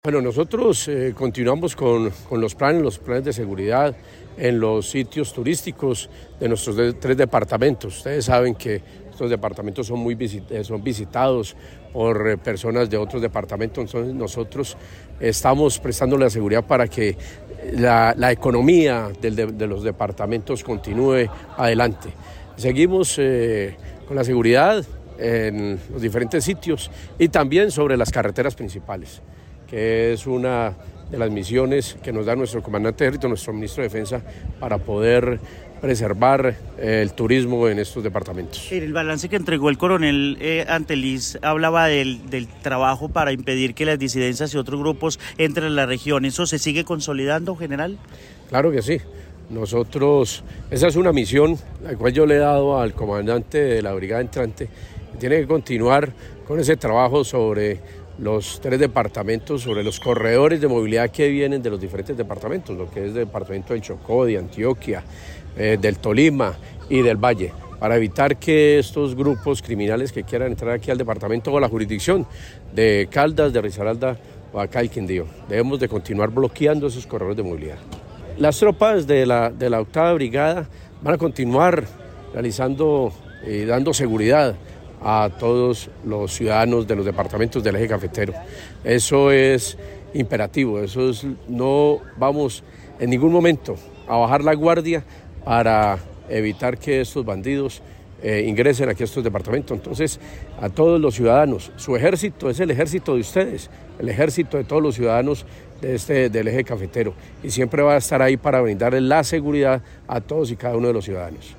El general José Bertulfo Soto Sánchez, comandante de la Quinta División del Ejército